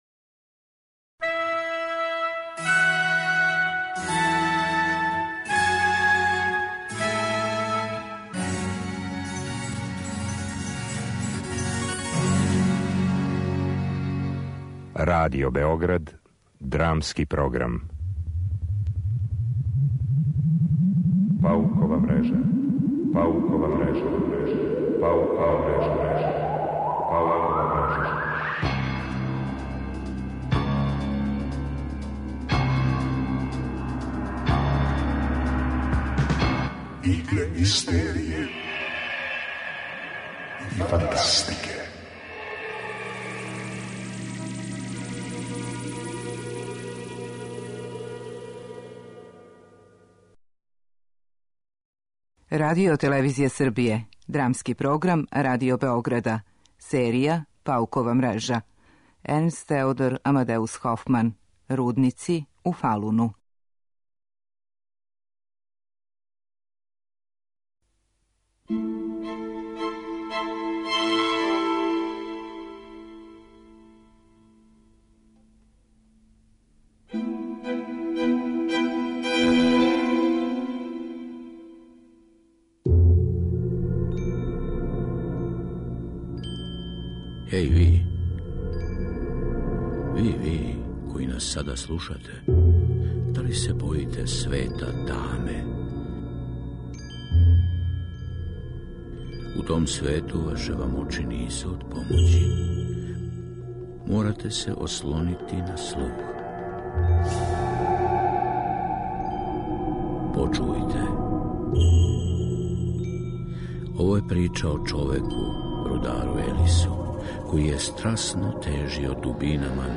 Драмски програм: Паукова мрежа: Рудници у Фалуну
Радио-адаптација прозе немачког романтичара Е.Т.А. Хофмана је прича о Елису Фребому, морнару који је под утицајем мрачних сила оличених у нестварном лику старог рудара Торберна напустио брод и запутио се у дубоке руднике Фалуна.